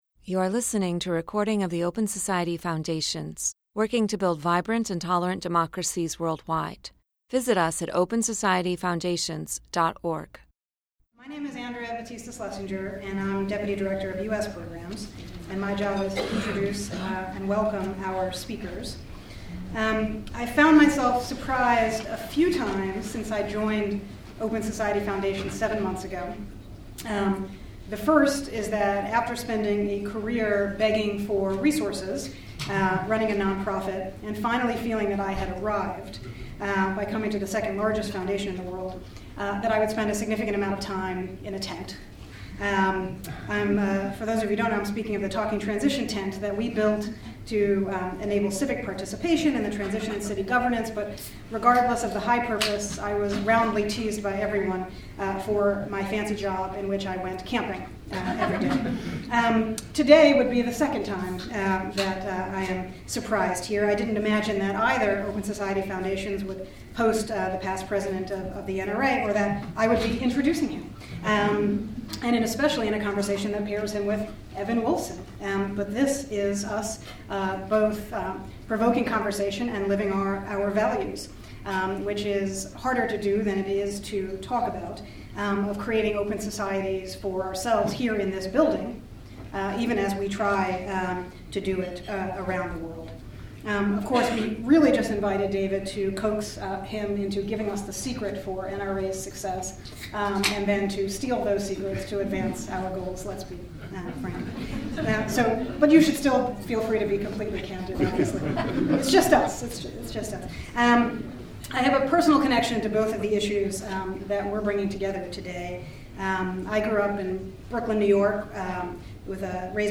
A discussion with leaders of Freedom to Marry and the National Rifle Association. What can we learn from their success in changing U.S. constitutional law?